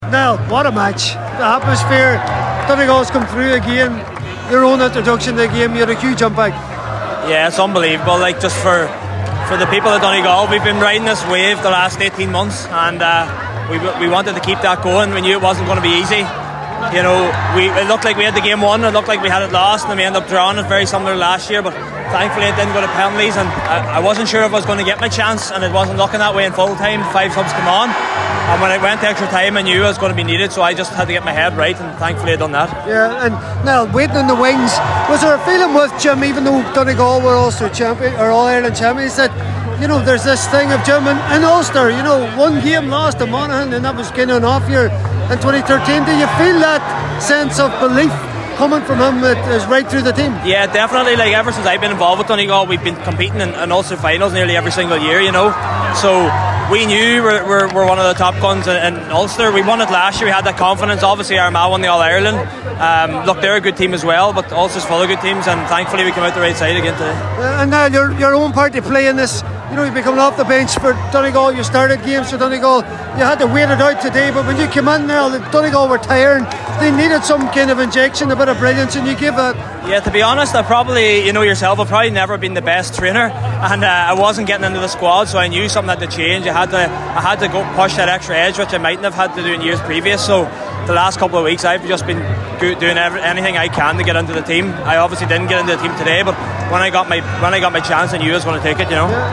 caught up with a number of the Donegal players after an absorbing Ulster SFC decider in Clones